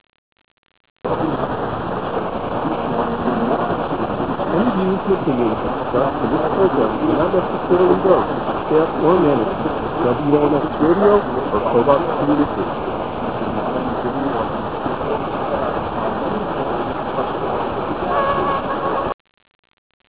This page contains DX Clips from the 2005 DX season!